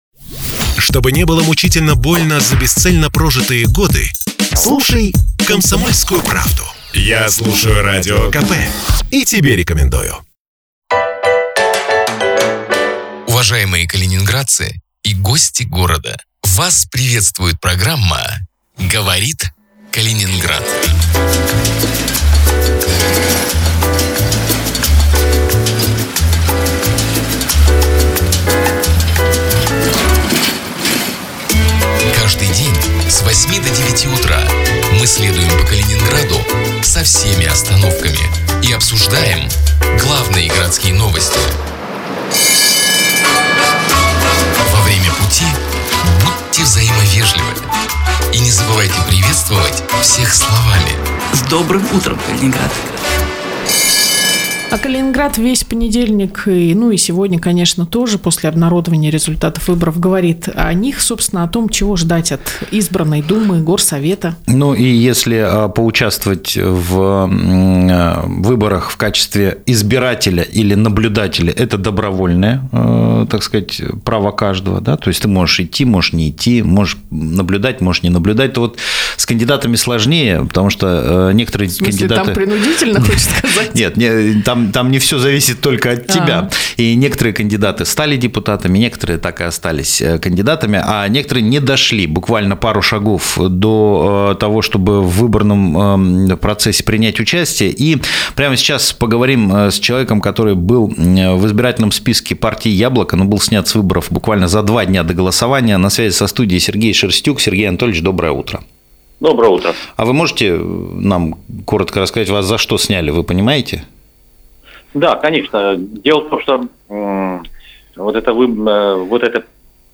Каждое утро в прямом эфире обсуждаем городские новости.